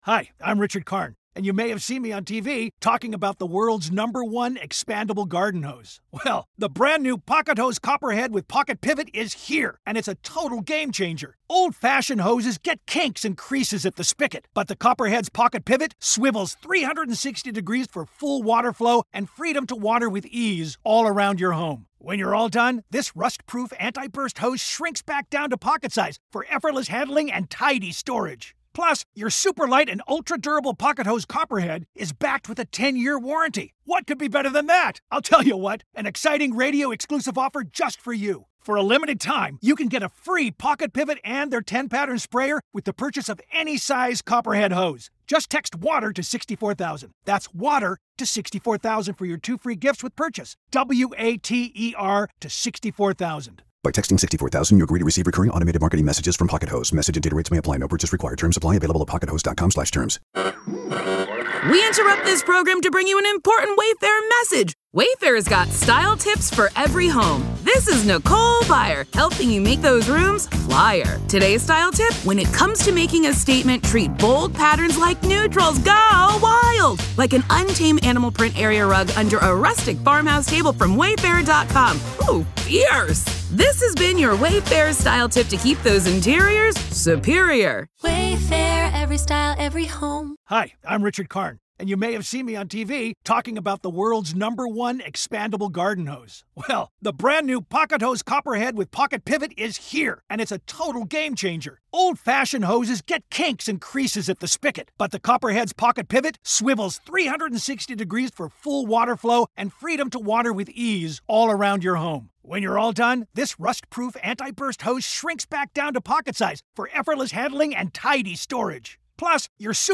Tune in as we connect the dots, hear shocking calls from listeners, and lay out a plan to uncover the truth.